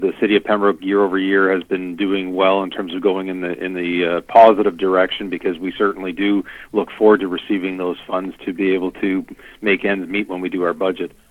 Gervais notes that although Pembroke has consistently received a favourable sum from the Province, not all municipalities fare quite as well so he is thankful for the annual stipend: